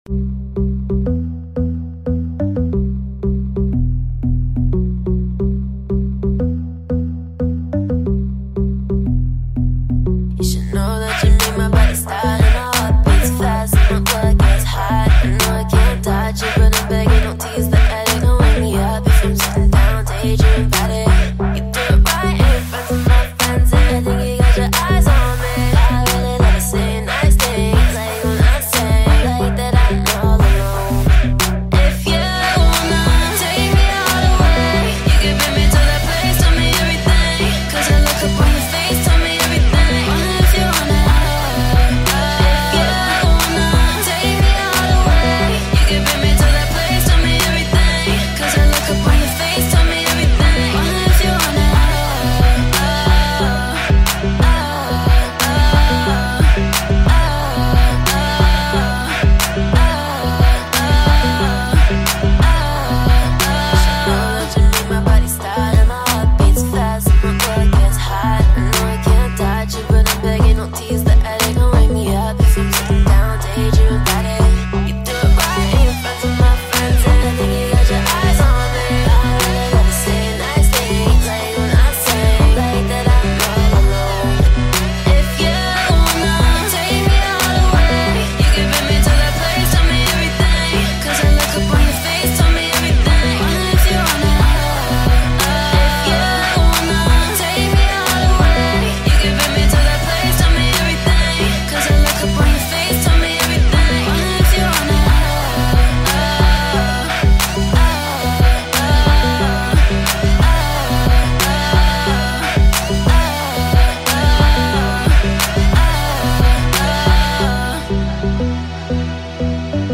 BPM90
Audio QualityPerfect (High Quality)